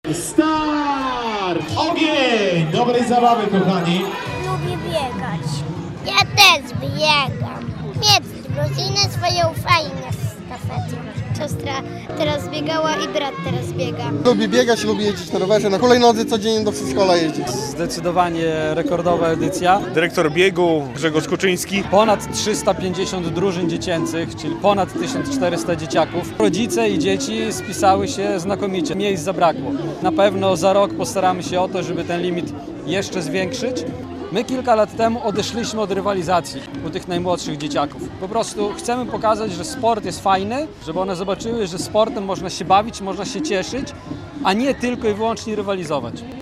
Ponad 1400 małych biegaczy wzięło udział w dziecięcej sztafecie Plum Ekiden Mini w Białymstoku - relacja